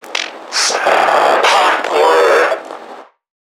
NPC_Creatures_Vocalisations_Infected [82].wav